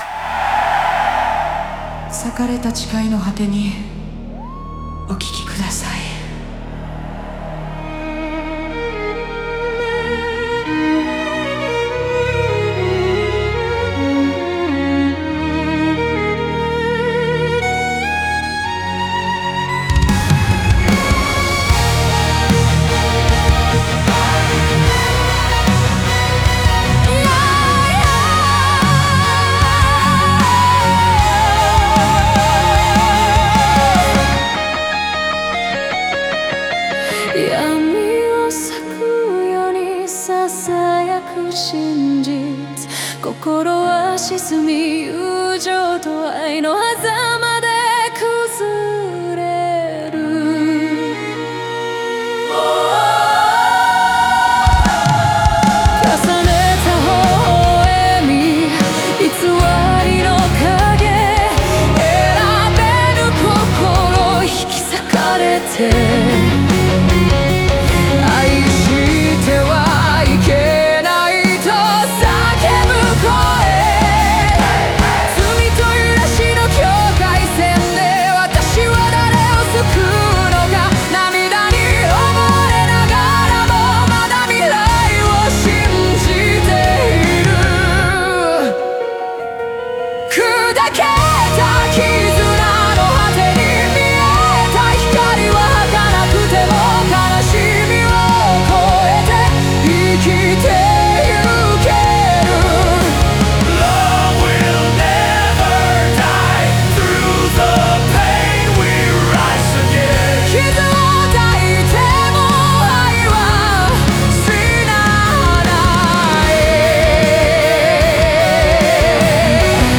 ギターソロやアウトロでは感情の爆発と解放感を描き、ラストには未来への希望を響かせます。